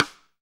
SD RI38.wav